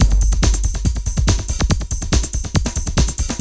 ___BIG BEAT 1.wav